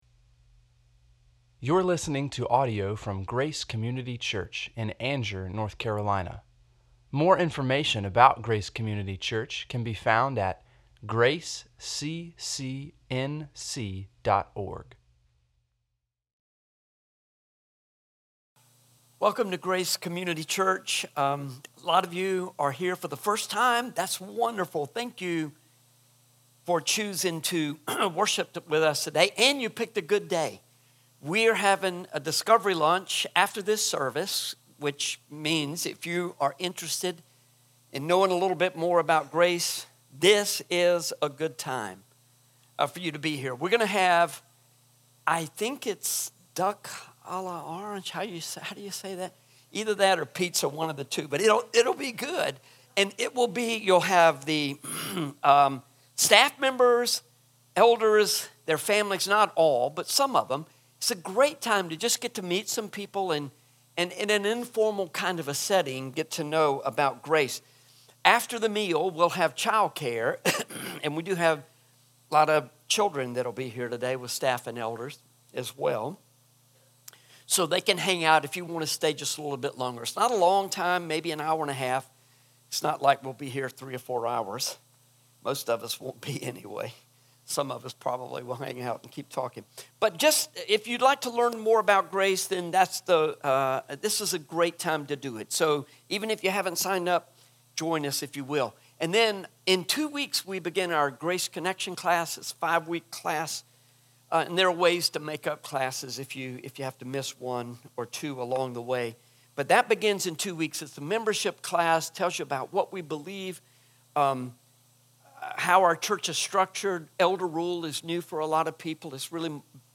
sermon-5-4-25.mp3